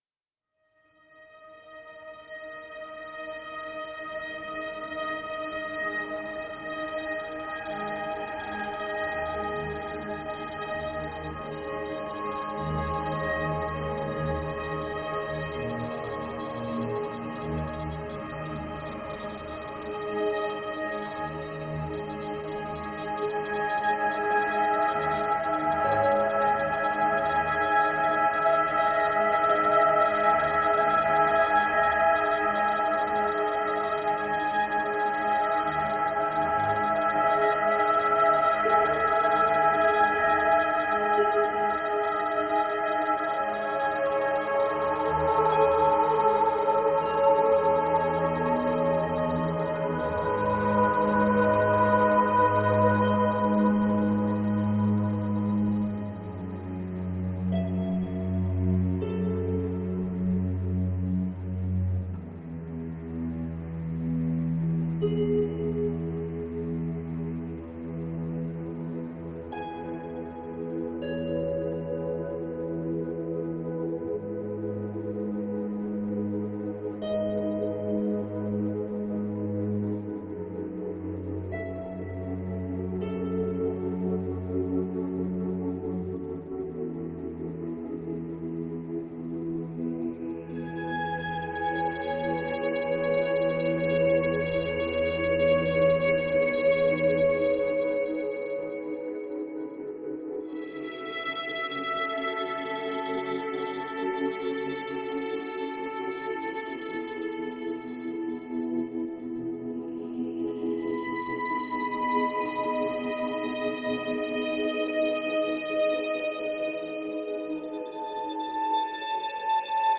* الموسيقى أعلاه تعود للموسيقى التصويرية للفيلم .